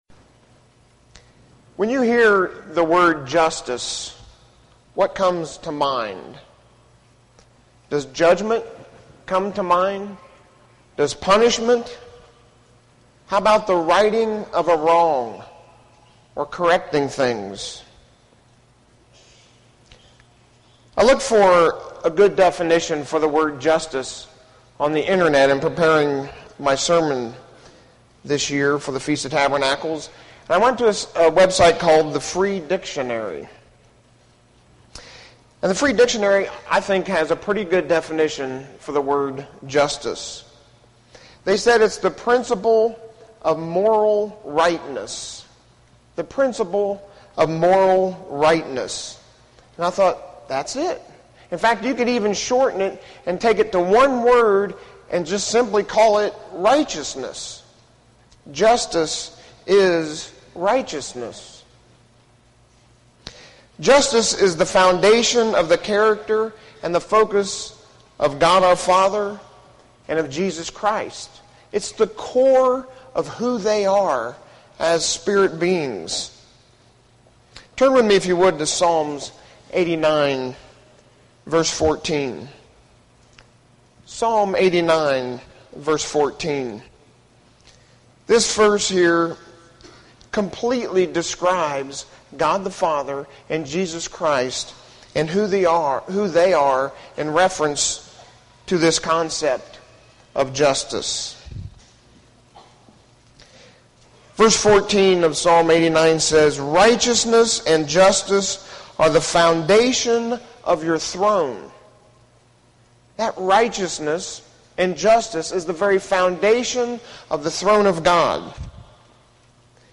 This sermon was given at the Oceanside, California 2013 Feast site.